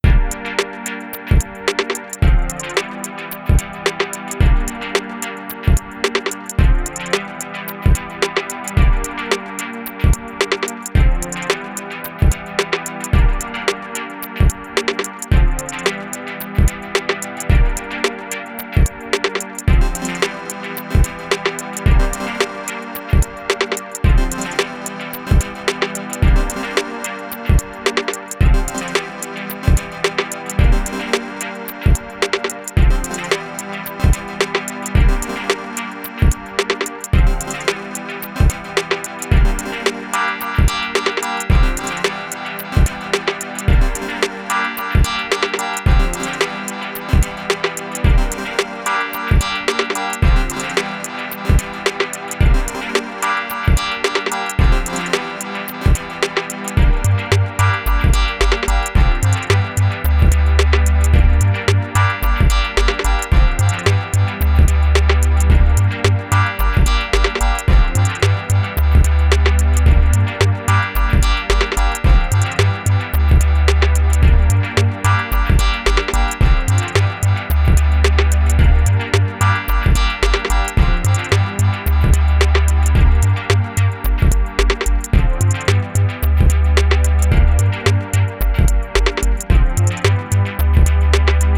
Rich and steppy electronics
House